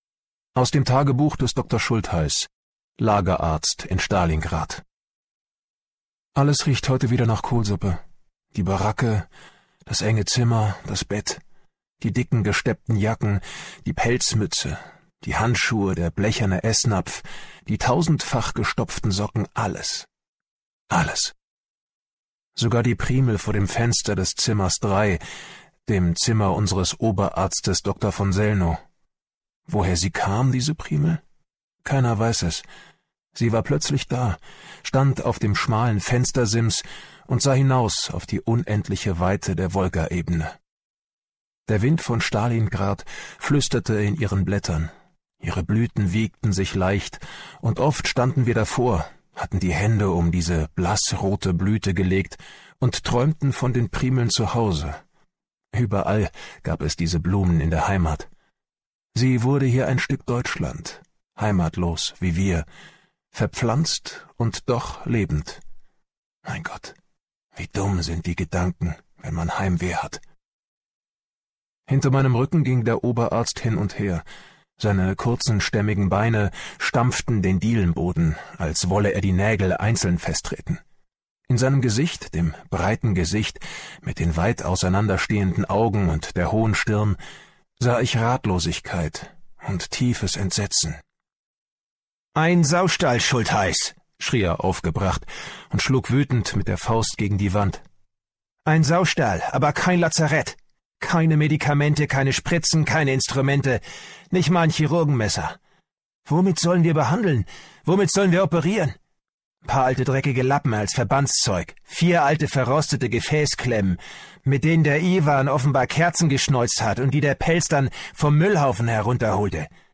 Götz Otto (Sprecher)